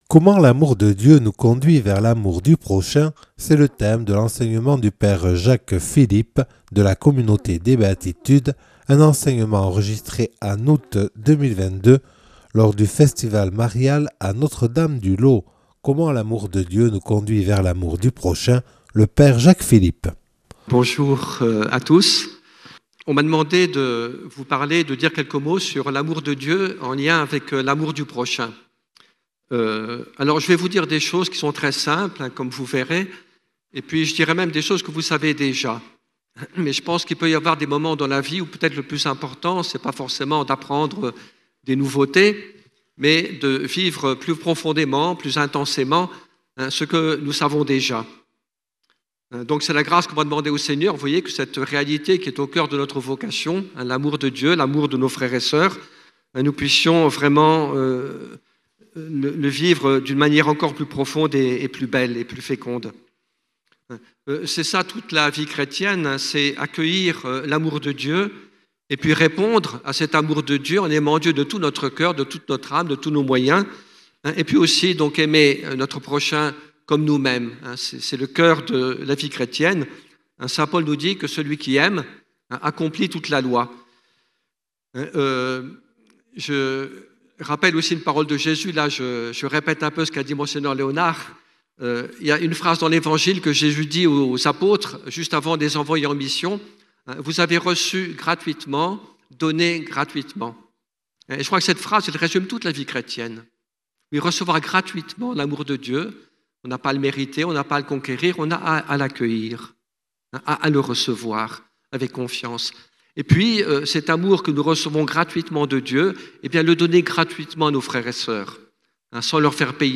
Conférence du Père Jacques Philippe de la communauté des Béatitudes